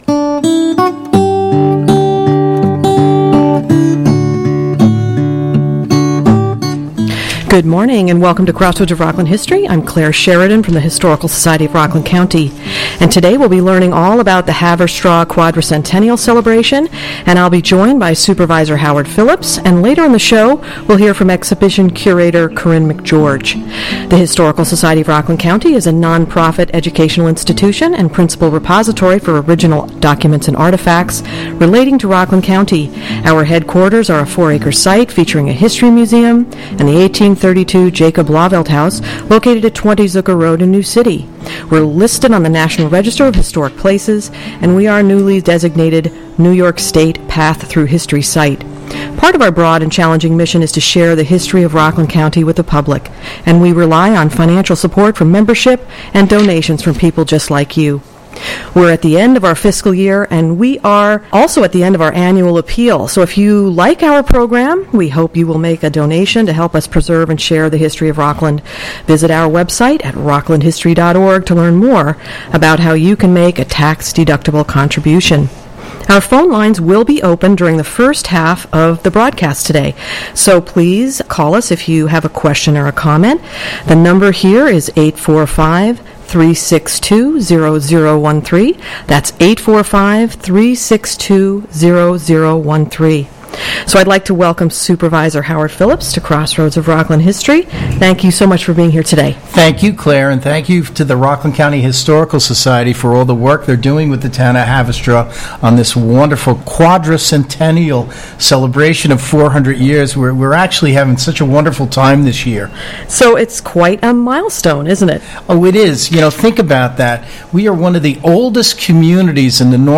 400HPradio.mp3